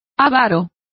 Complete with pronunciation of the translation of stingiest.